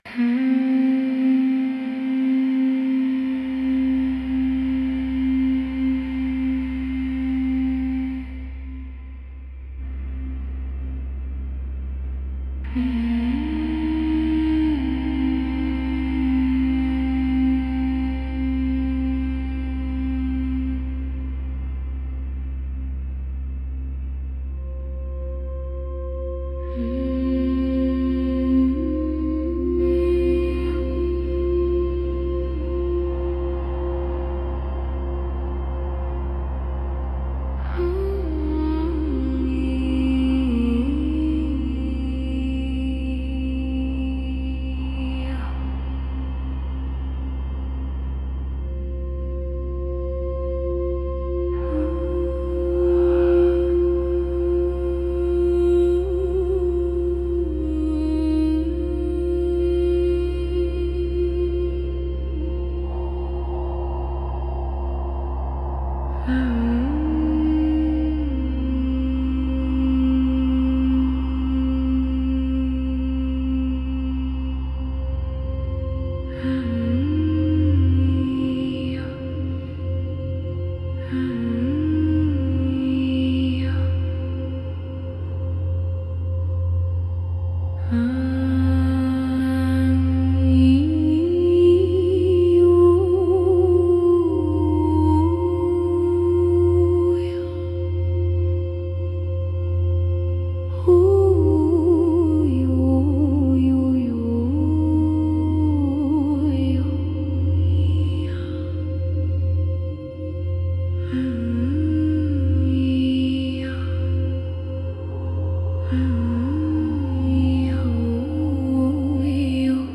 частота 528 ГЦ